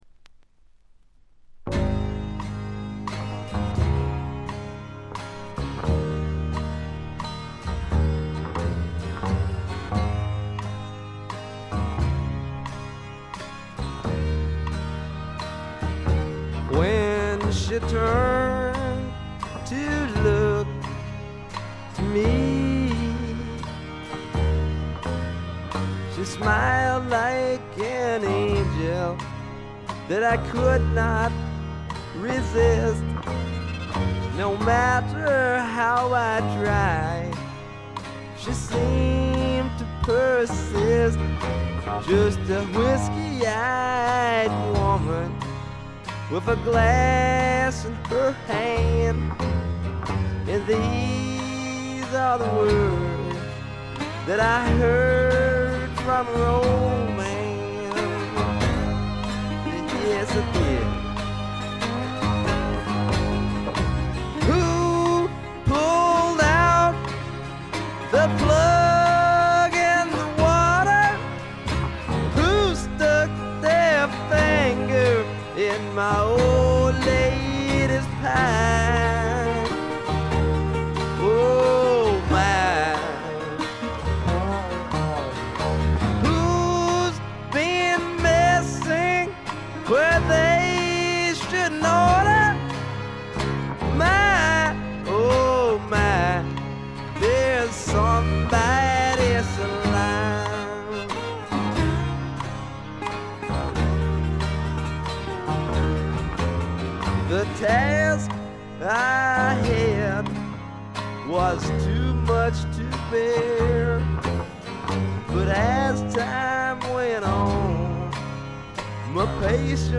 ほとんどノイズ感無し。
よりファンキーに、よりダーティーにきめていて文句無し！
試聴曲は現品からの取り込み音源です。